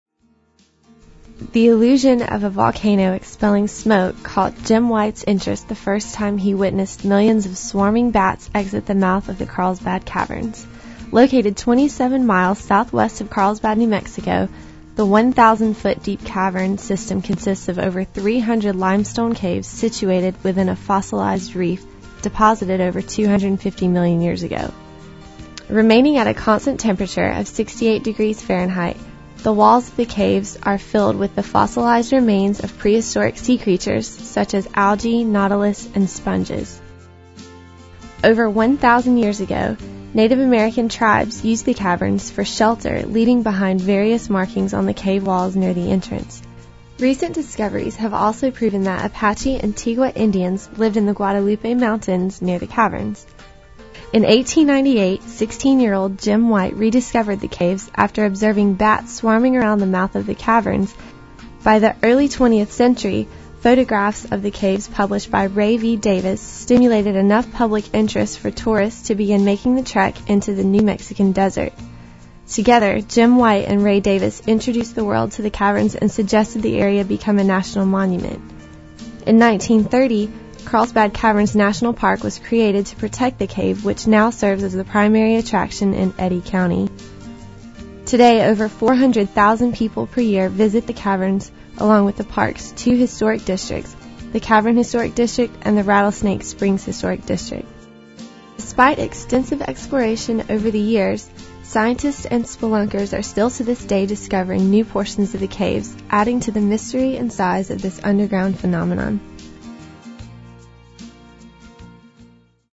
Podcast Written and Narrated